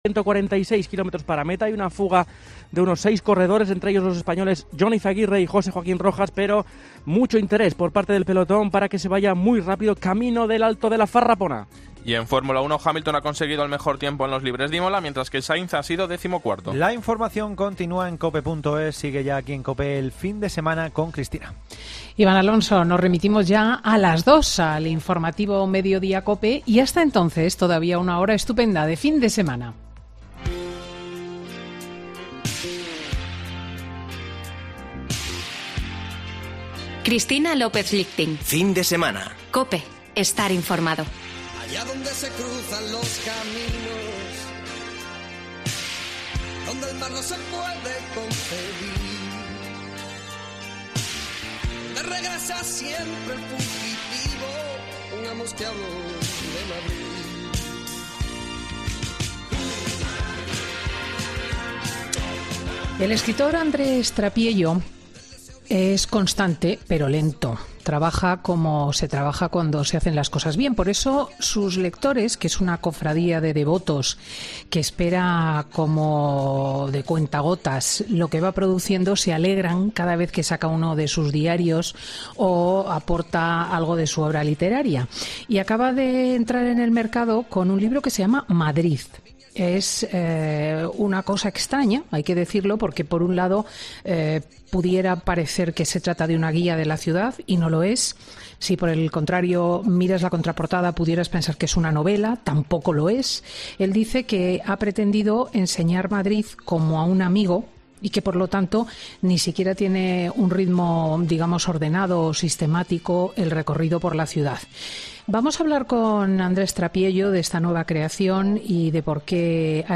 El escritor ha estado cinco años para escribir su nuevo libro y cuenta en Fin de Semana con Cristina cómo lo ha gestado